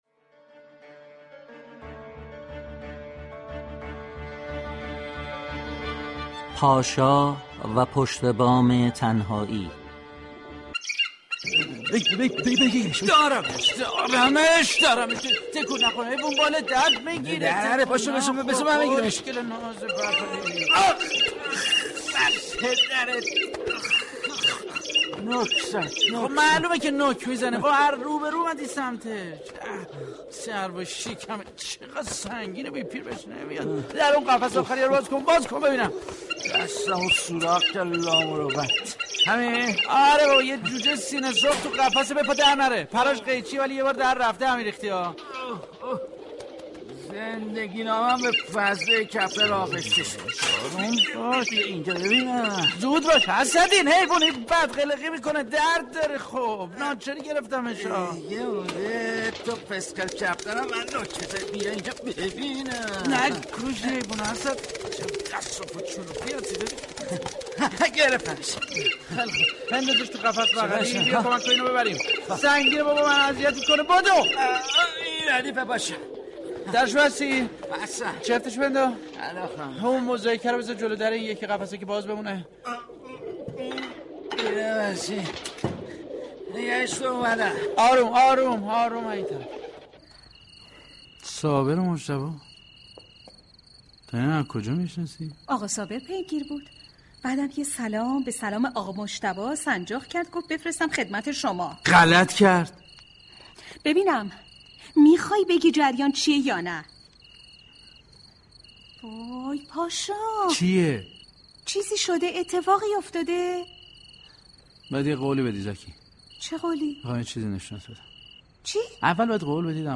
به گزارش روابط عمومی اداره كل هنرهای نمایشی رادیو، این نمایش رادیویی را مجید دیندار نوشته و داستان مردی است كه بعد از تعطیلی كارخانه اش بیشتر وقتش را به تنهایی سپری می كند تا اینكه بالاخره .....